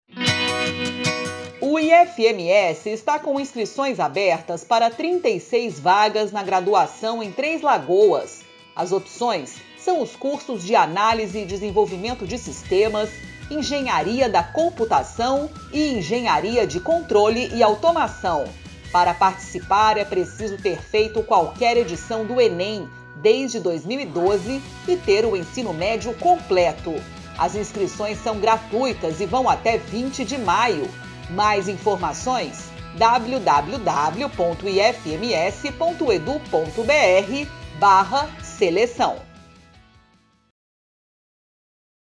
Spot - Cursos de graduação para o 2º semestre de 2022 em Três Lagoas
Áudio enviado às rádios para divulgação institucional do IFMS.